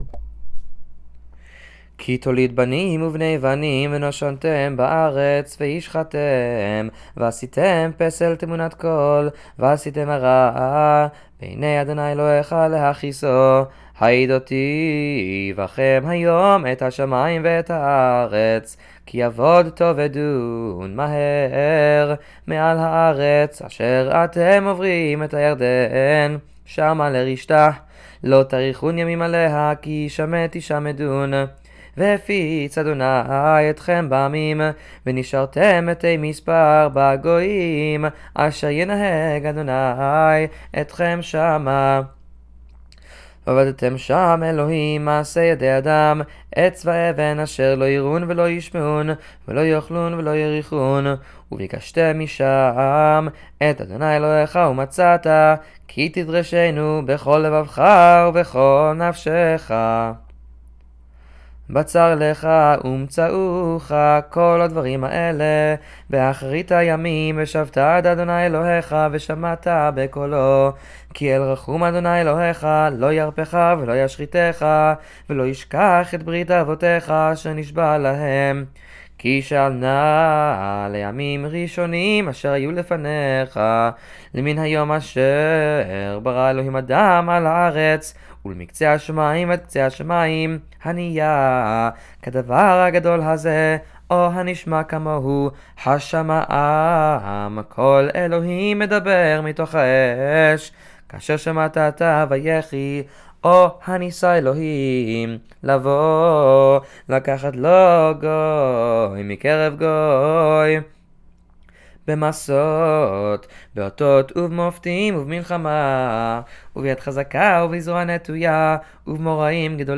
Torah reading for the 9th of Av – Morning
9th-of-Av-Torah-Shacharit.mp3